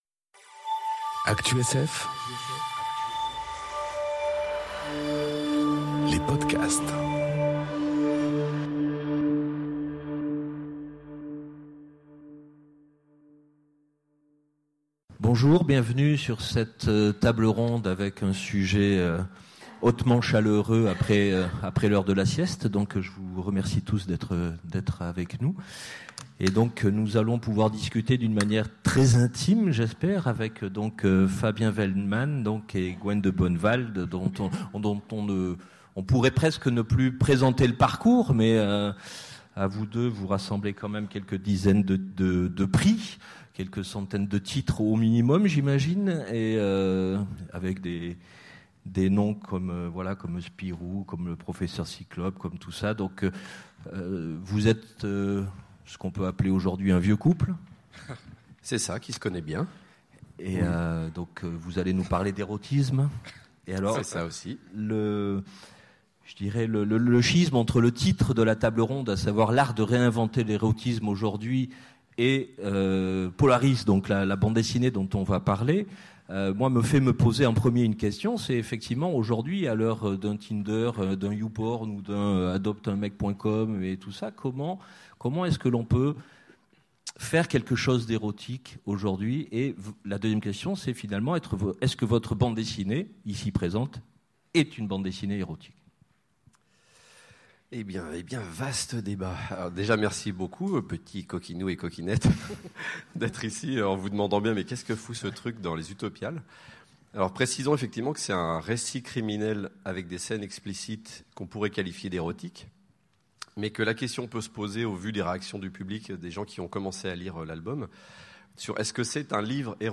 Conférence L’art érotique à réinventer ? aux Utopiales 2018